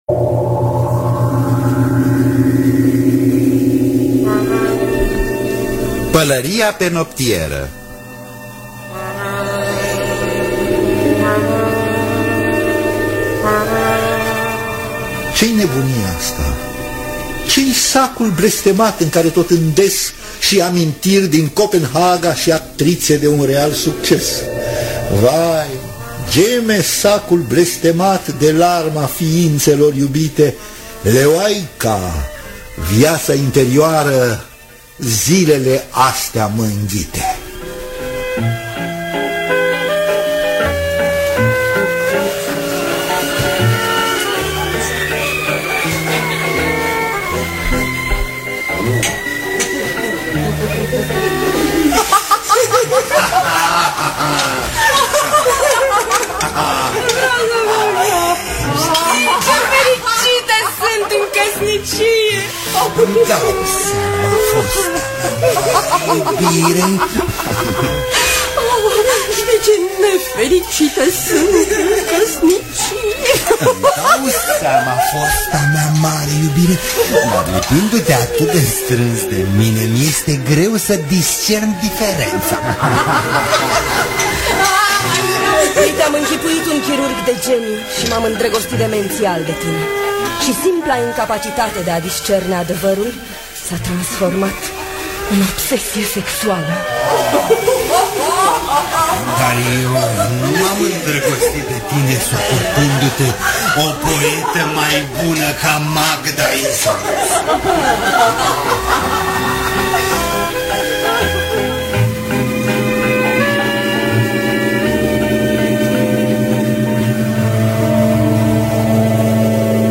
Pălăria pe noptieră de Teodor Mazilu – Teatru Radiofonic Online